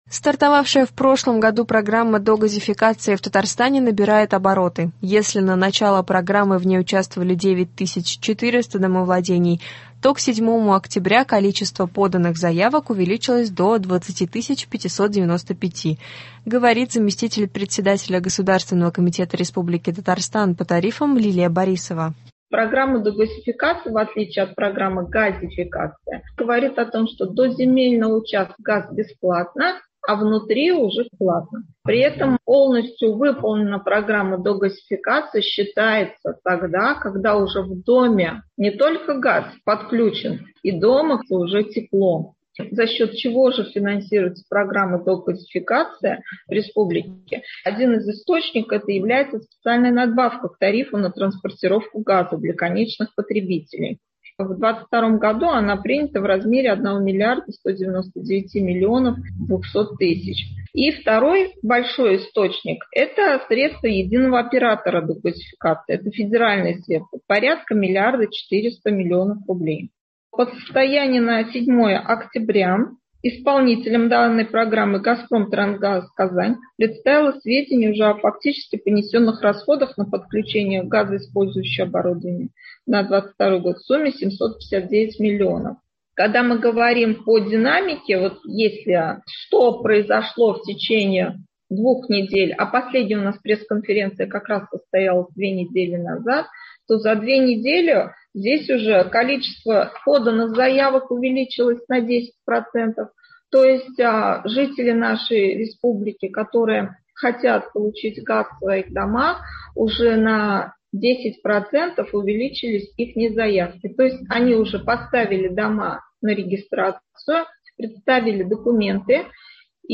Стартовавшая в прошлом году программа догазификации в Татарстане набирает обороты. Если на начало программы в ней участвовали 9400 домовладений, то к 7 октября количество поданных заявок увеличилось до 20 595. Говорит заместитель председателя Государственного комитета РТ по тарифам Лилия Борисова.